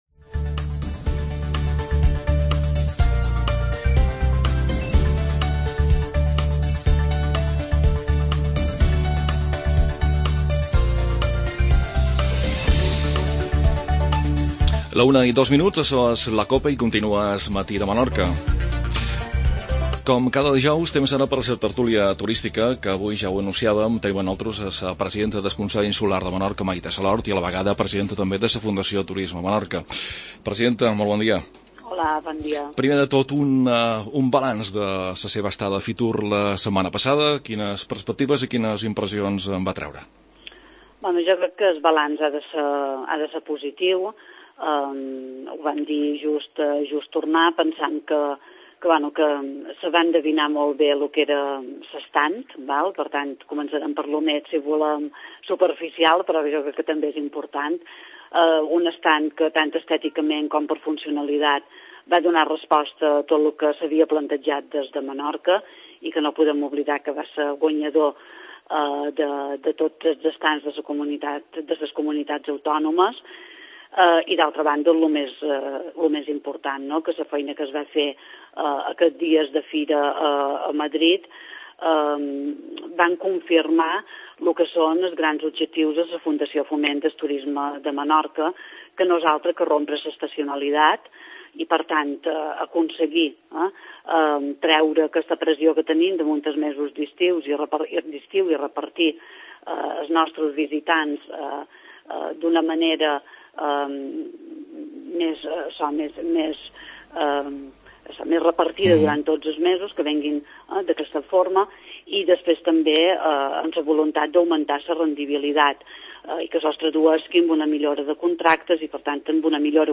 AUDIO: Tertluia turstica. Entrevista a la presidenta del Consell i presidenta de la Fundacio Turisme Menorca Maite Salord